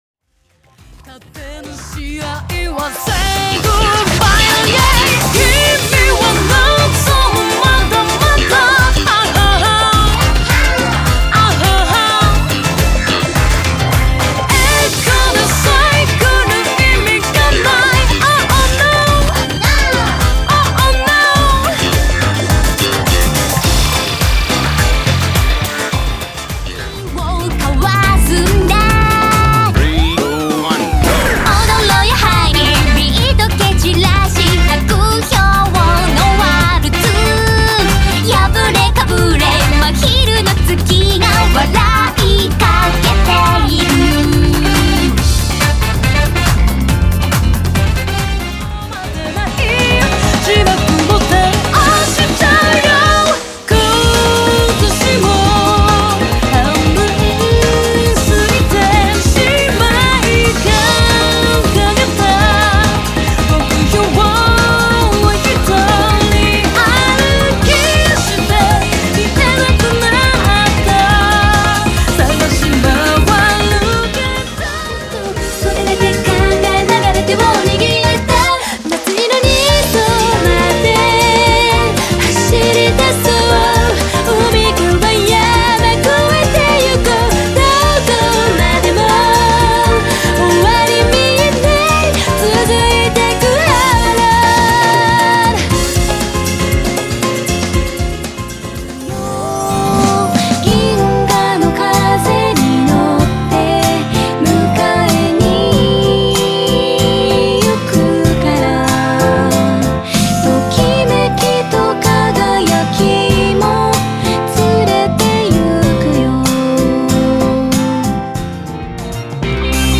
Electric Violin
<全曲クロスフェード試聴>LinkIcon